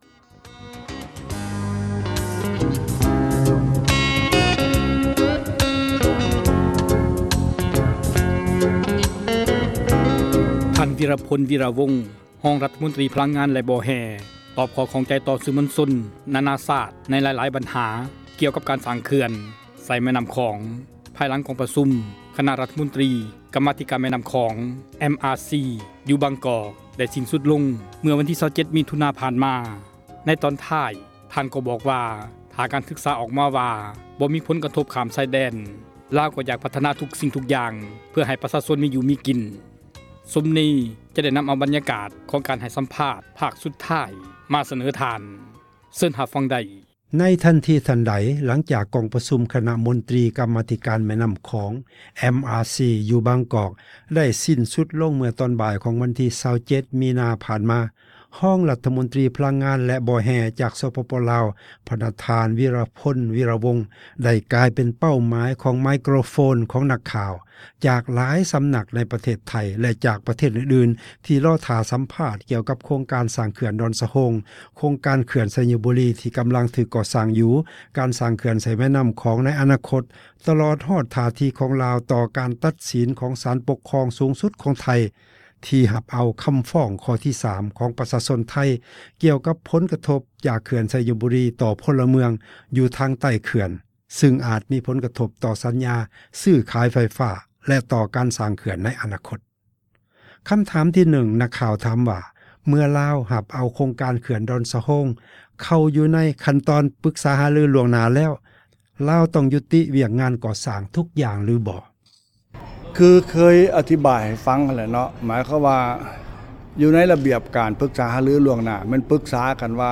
ສັມພາດທ່ານວິຣະພົນ ວິຣະວົງ (ຕໍ່/ຈົບ)
ທ່ານ ວິຣະພົນ ວີຣະວົງ ຮອງ ຣັຖມົນຕຣີ ພລັງງານ ແລະ ບໍ່ແຮ່ ຕອບ ຂໍ້ຂ້ອງໃຈ ຕໍ່ ສື່ມວນຊົນ ໃນຫລາຍ ບັນຫາ ກ່ຽວກັບ ການສ້າງ ເຂື່ອນ ໃສ່ ແມ່ນໍ້າຂອງ ພາຍຫລັງ ກອງປະຊຸມ ຄະນະ ຣັຖມົນຕຣີ ກັມມາທິການ ແມ່ນໍ້າຂອງ ຢູ່ ບາງກອກ.